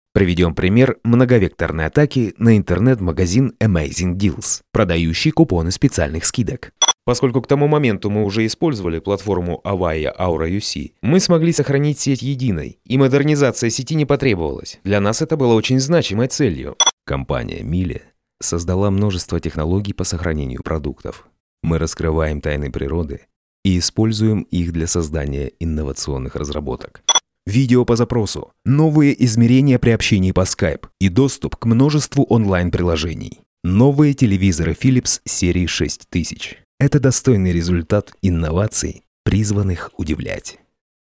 Native Russian Voice Over Talent
Sprechprobe: Industrie (Muttersprache):